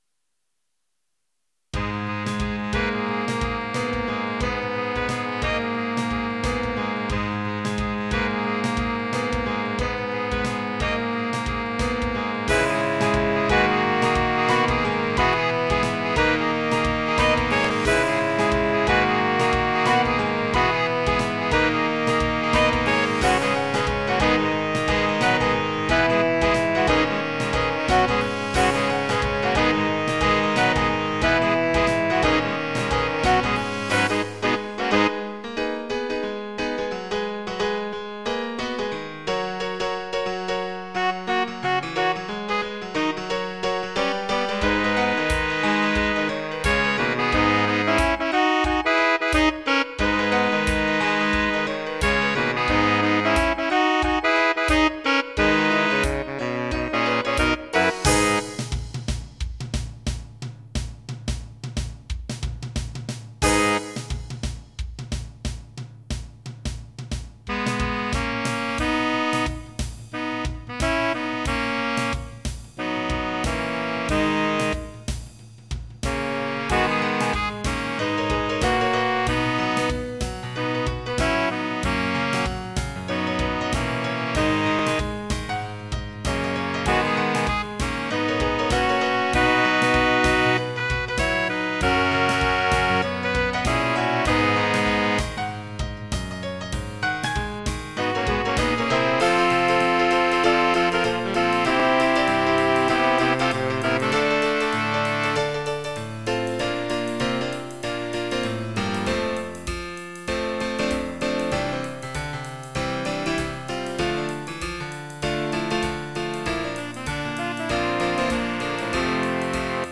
Tags: Quartet, Piano, Woodwinds, Percussion
Title Joyride Opus # 196 Year 0000 Duration 00:03:02 Self-Rating 4 Description With the top down, and the shades on. mp3 download wav download Files: mp3 wav Tags: Quartet, Piano, Woodwinds, Percussion Plays: 2379 Likes: 0